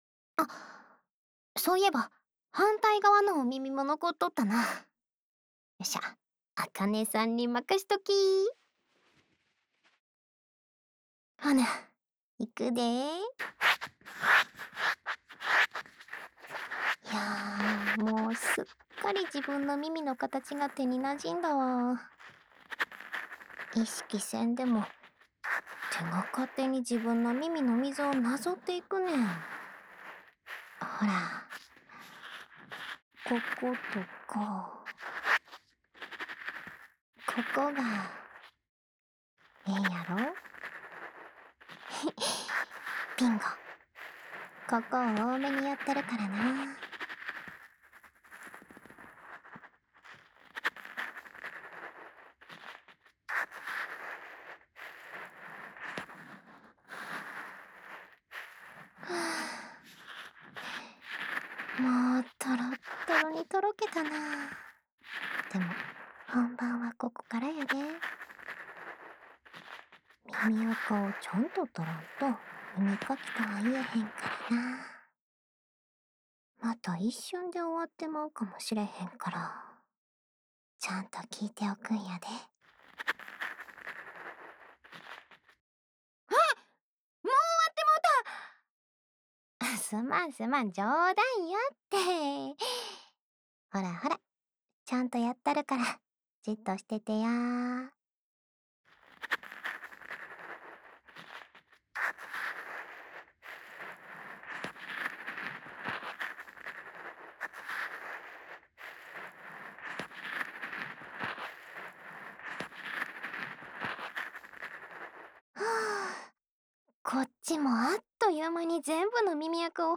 家中喝酒 ASMR
关西腔的同学